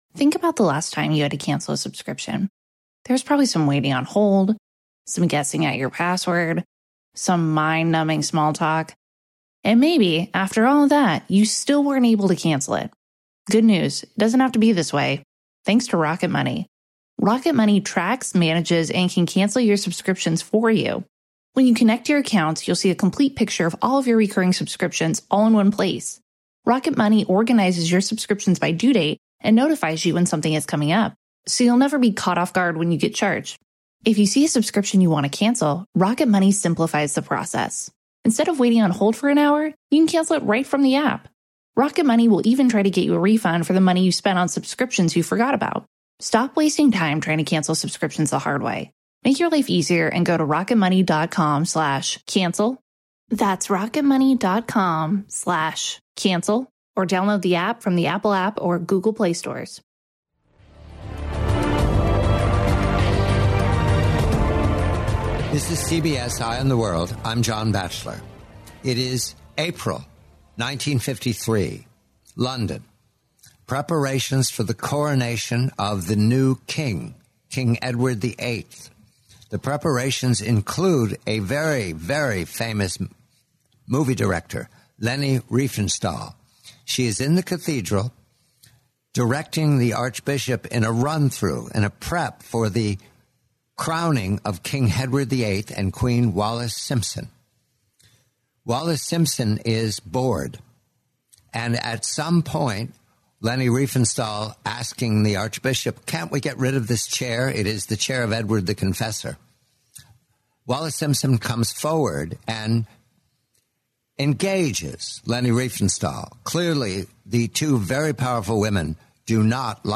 The complete, 20-minute interview